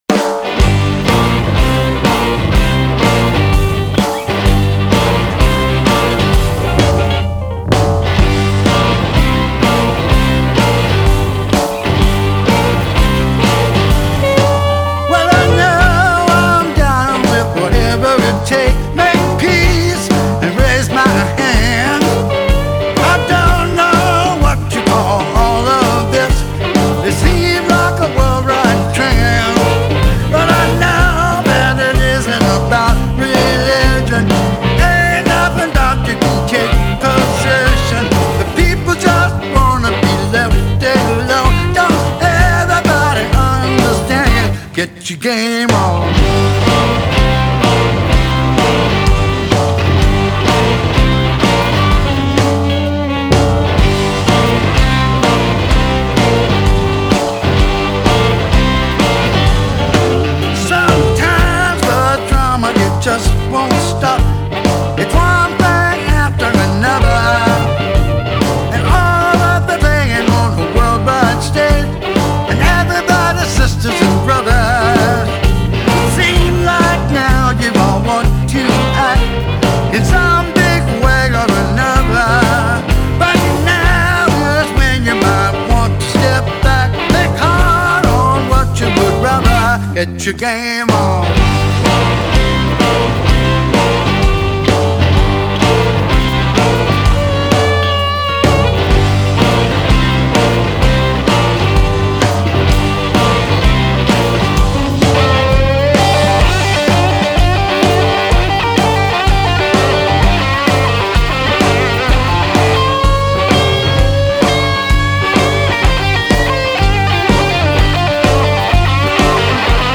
Genre: Blues, Blues Rock